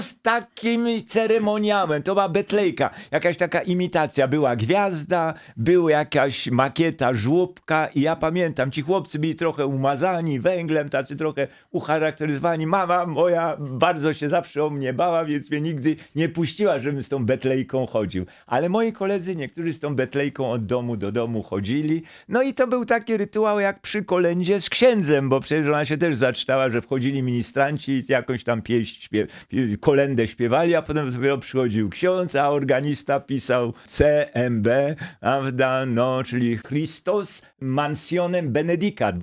Językoznawca, prof. Jan Miodek odwiedził w pierwszy dzień świąt studio Radia Rodzina.